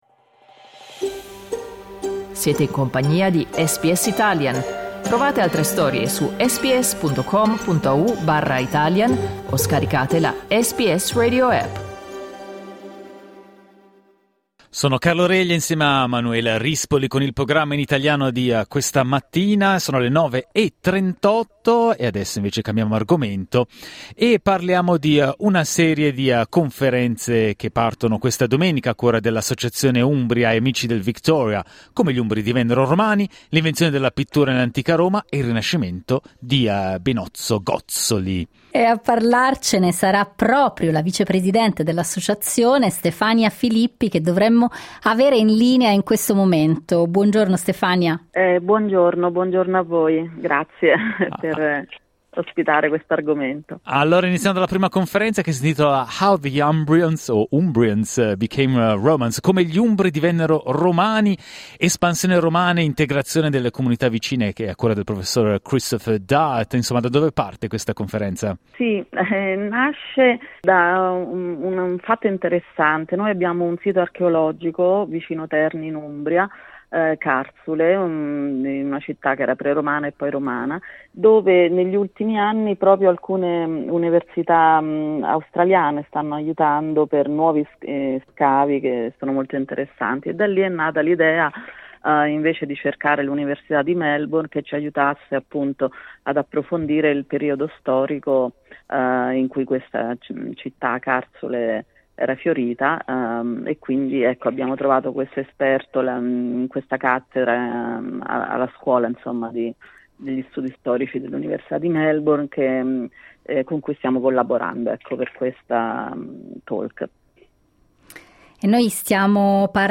Clicca sul tasto "play" in alto per ascoltare l'intervista Ascolta anche: Antonio Albanese ci racconta il suo "Cento Domeniche" SBS Italian 12:28 Italian Ascolta SBS Italian tutti i giorni, dalle 8am alle 10am.